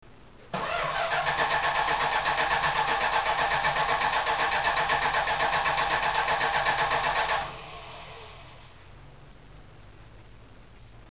cranking and
440_Crank.mp3